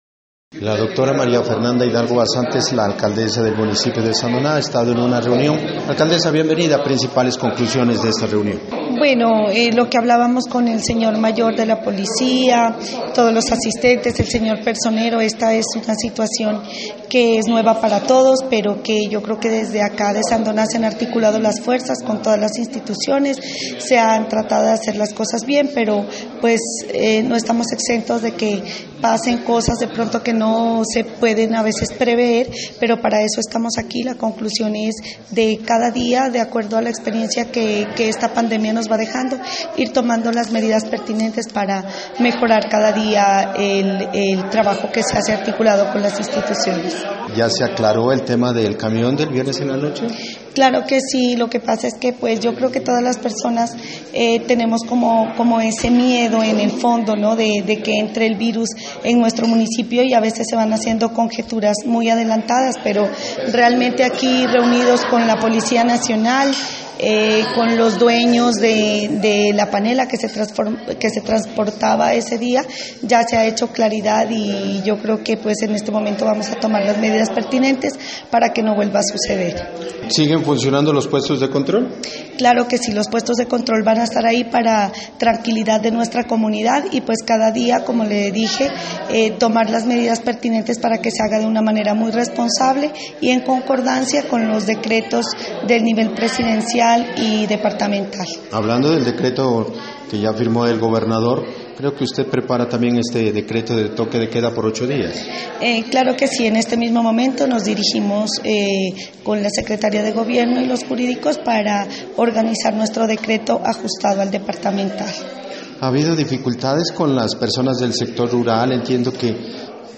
Entrevista con la alcaldesa María Fernanda Hidalgo Basante: